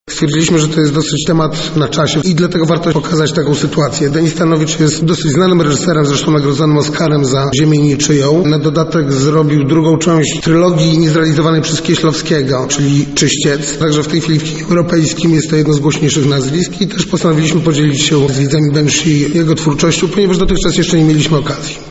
mówi prowadzący